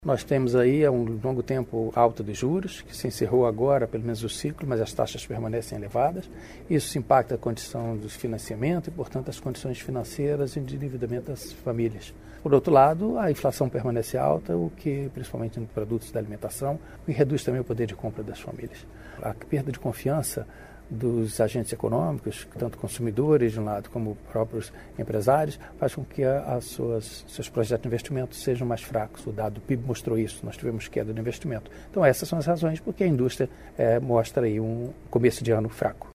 Ele explica ao Portal da Indústria que o desaquecimento da atividade é resultado  das dificuldades da economia.